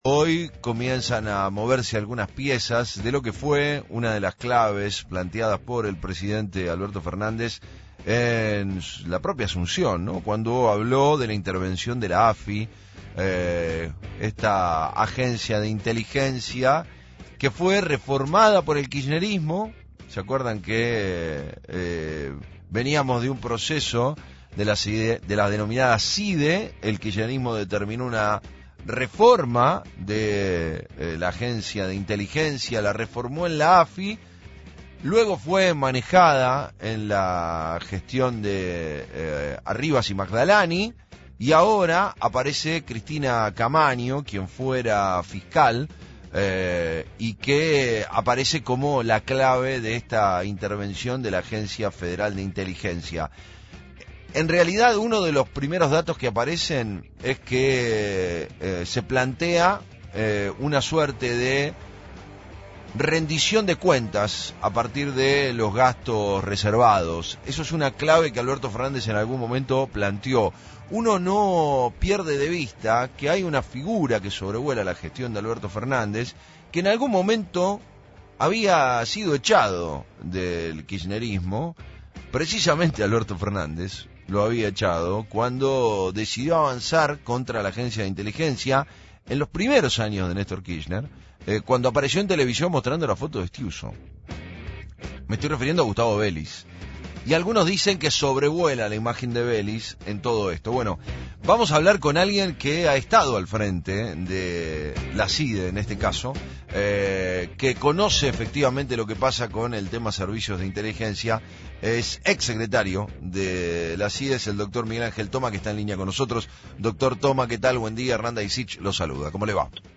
En diálogo con FRECUENCIA ZERO el ex secretario de la SIDE, Miguel Ángel Toma, se refirió a la reestructuración de la AFI (ex SIDE), que impulsa el gobierno de Alberto Fernández.